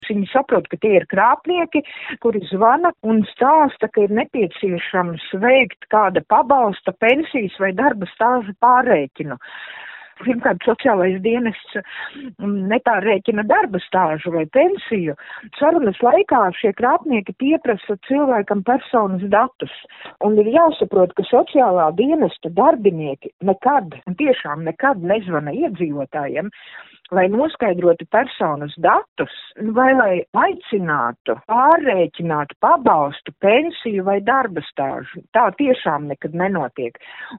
intervijā